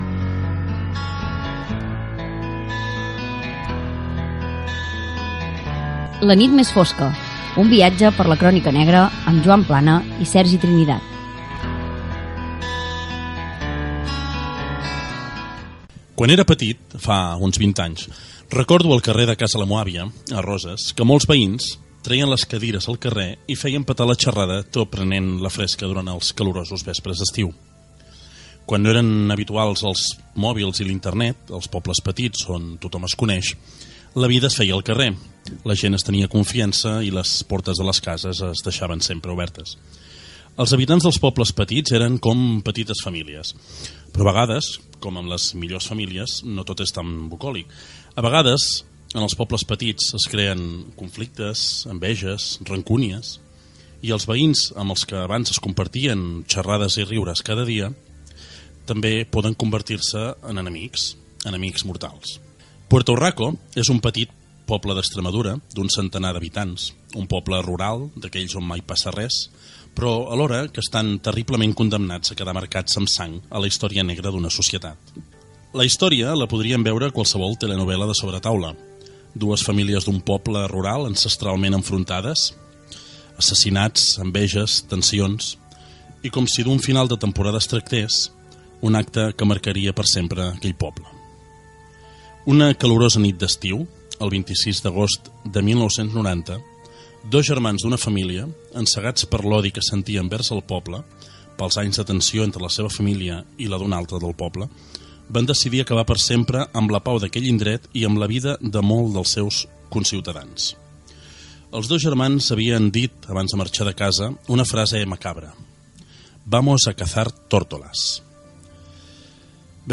Careta del programa, presentació i dades del programa dedicat als assassinats de Puerto Hurraco (Extremadura), a l'any 1990